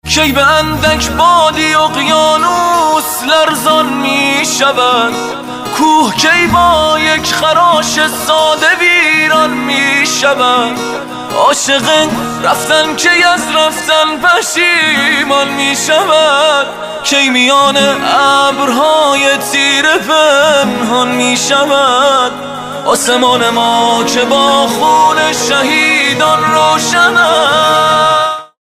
رینگتون حماسی و باکلام